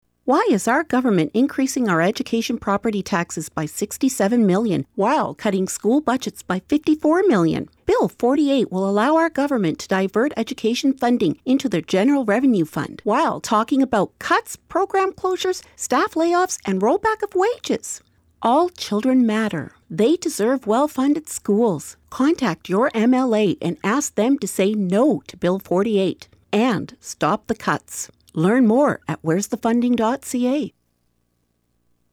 CUPE Education Workers’ Steering Committee launches new radio ad campaign